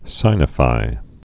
(sīnə-fī, sĭnə-)